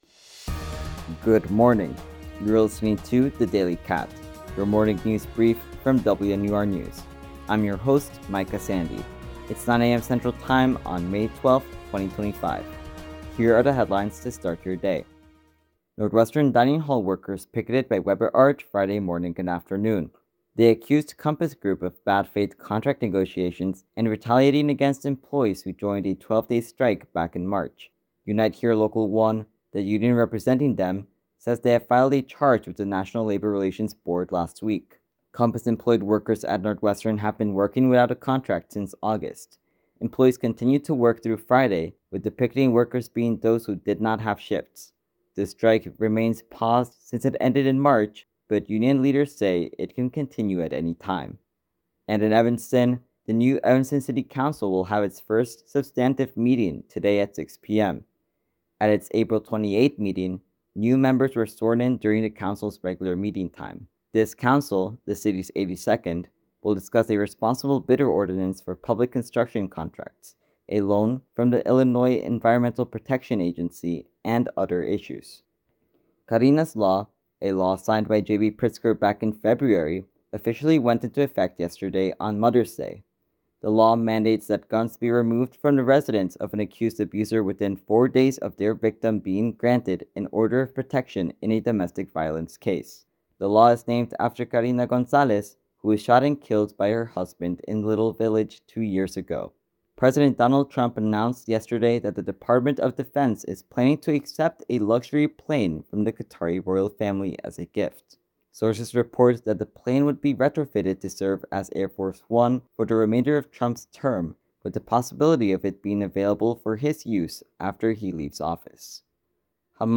May 12, 2025: Campus dining hall workers picket, Evanston City Council meeting, Karina’s Law, President Trump’s new incoming plane, Hamas’ last hostage. WNUR News broadcasts live at 6 pm CST on Mondays, Wednesdays, and Fridays on WNUR 89.3 FM.